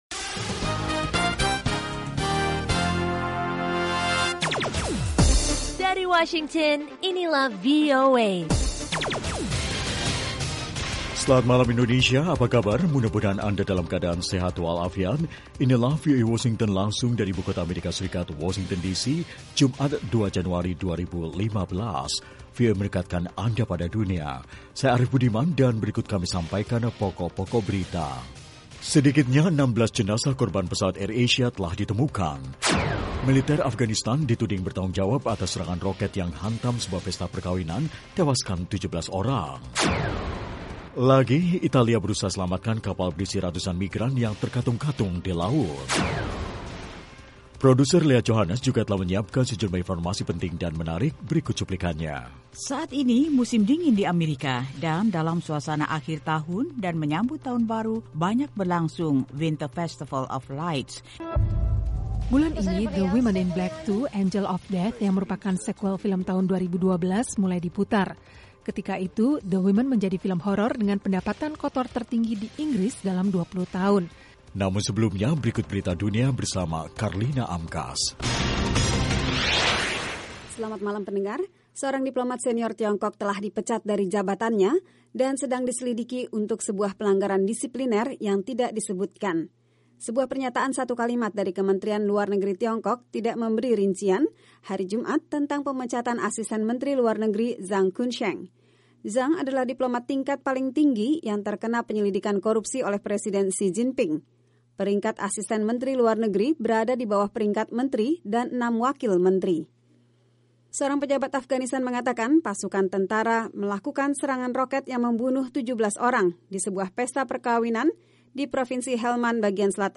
Juga acara musik lewat Top Hits, musik jazz dan country.